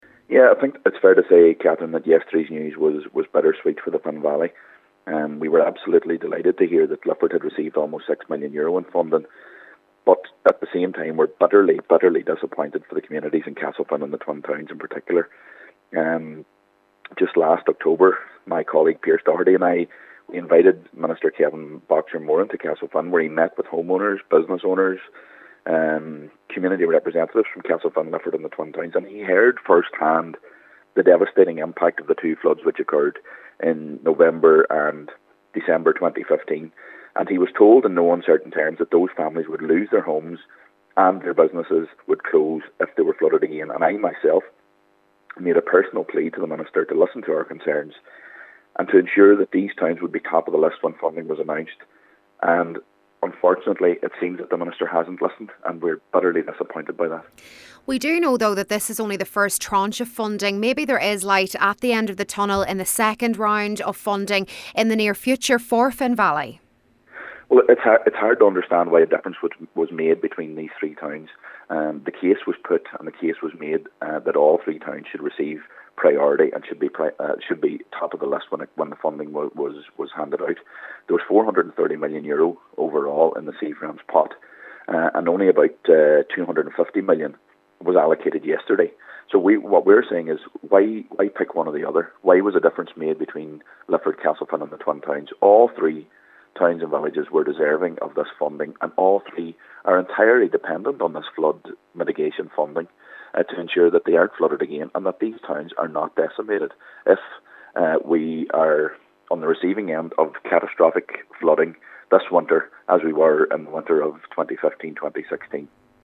Cllr Gary Doherty was speaking following yesterday’s announcement by An Taoiseach that the first tranche of €257m in funding will see 50 new flood relief schemes proceeding to detailed design and construction phase.